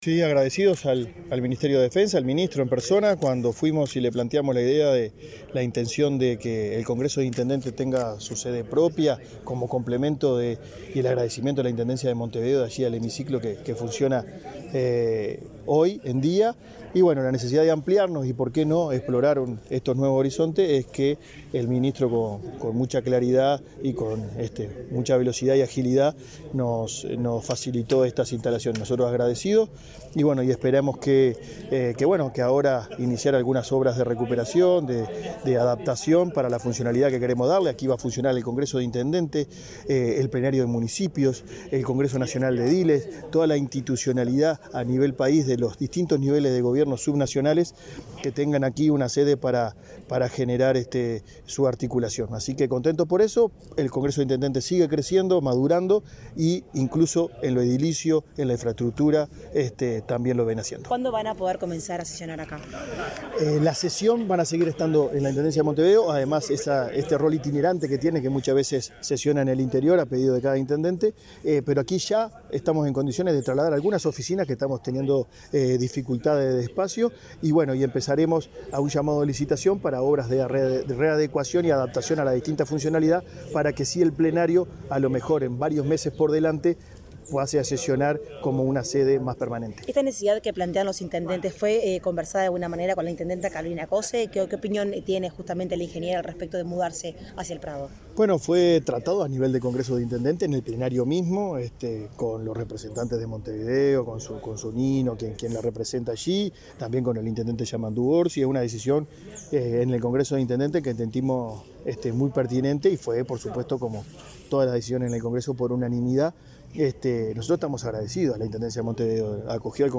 Declaraciones del presidente del Congreso de Intendentes
El presidente del Congreso de Intendentes, Guillermo López, destacó la importancia del acuerdo.